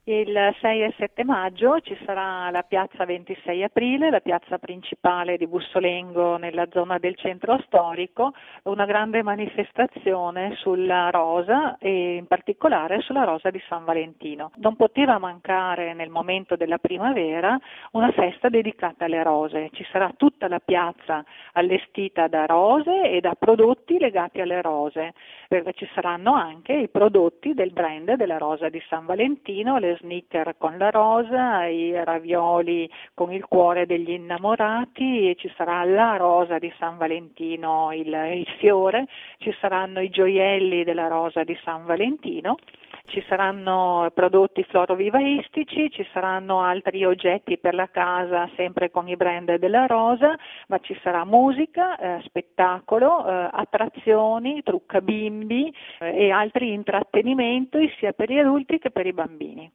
Non mancheranno intrattenimenti di vario genere per grandi e piccoli, come ci ha raccontato il sindaco di Bussolengo, Maria Paola Boscaini: